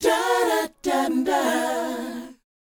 DOWOP F#4F.wav